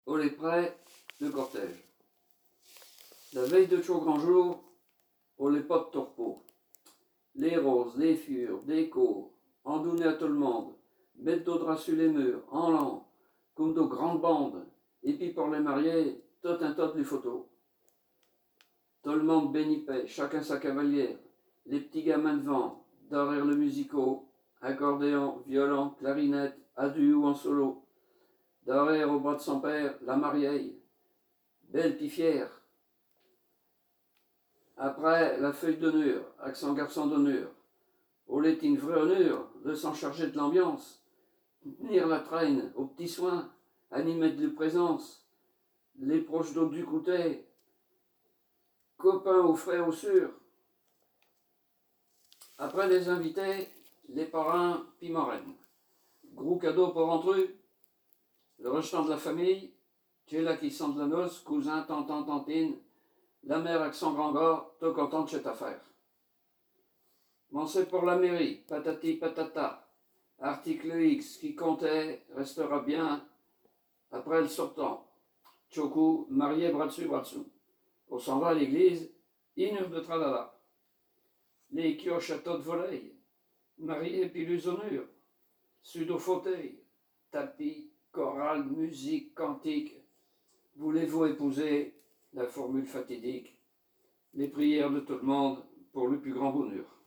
Poésies en patois